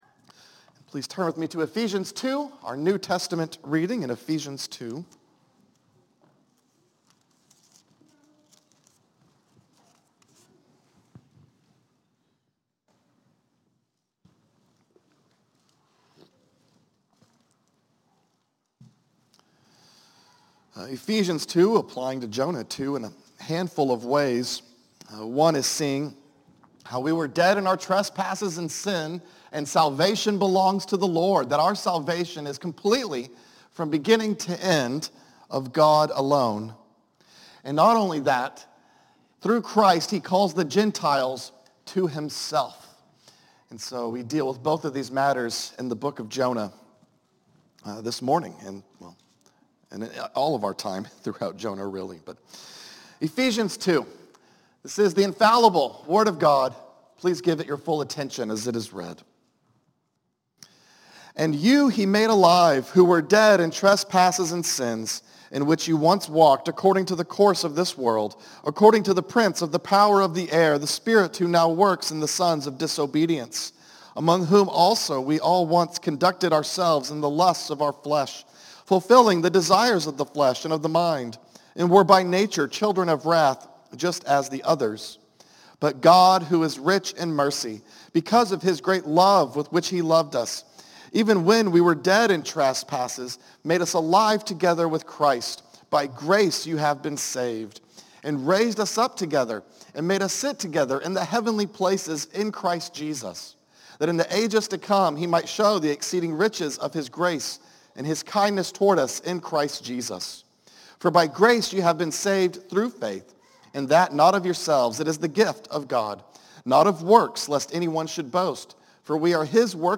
A message from the series "Jonah."